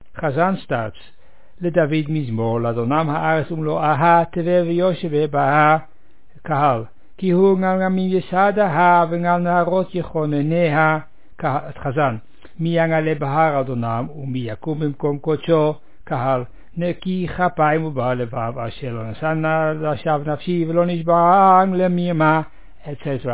The service at the unveiling of a Matseva (tombstone) at Beth Haim in Ouderkerk aan de Amstel.
recited verse by verse: first line by chazzan, second by kahal, third by chazzan etc.